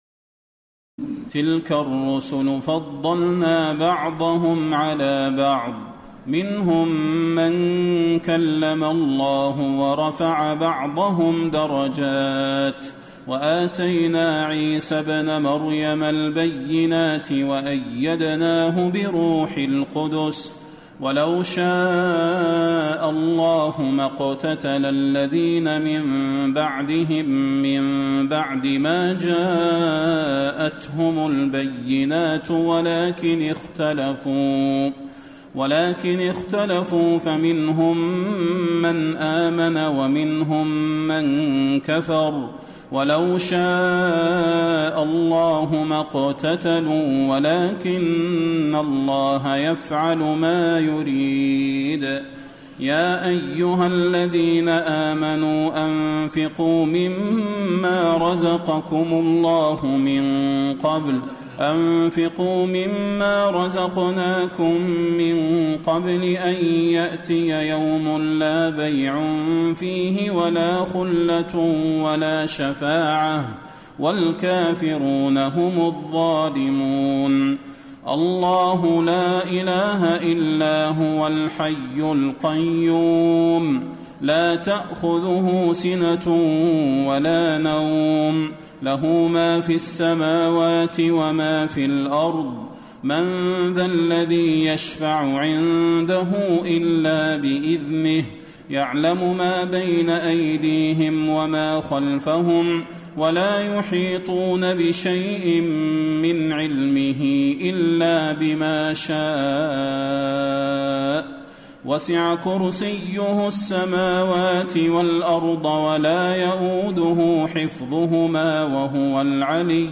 تهجد ليلة 23 رمضان 1434هـ من سورتي البقرة (253-286) و آل عمران (1-32) Tahajjud 23 st night Ramadan 1434H from Surah Al-Baqara and Aal-i-Imraan > تراويح الحرم النبوي عام 1434 🕌 > التراويح - تلاوات الحرمين